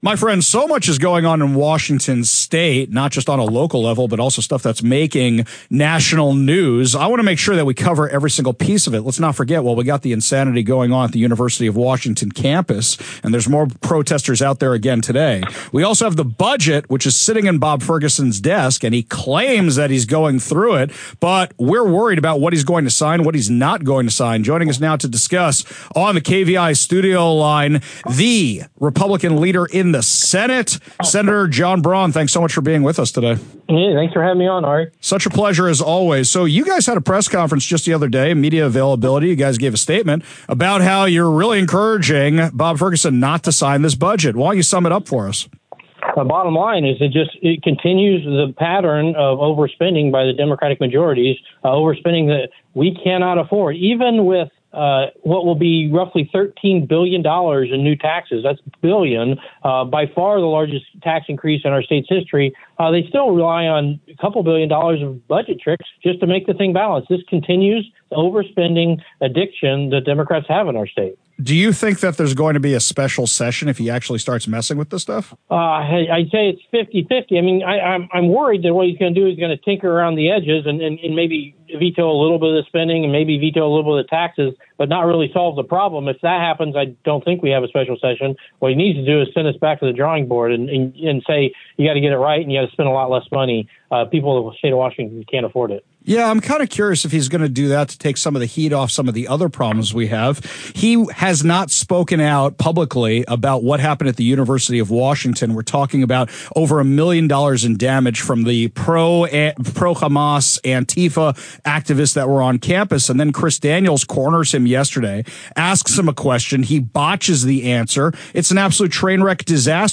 He critiques overspending, calls for accountability, and urges leadership to address rising tensions. A candid conversation on pressing state issues.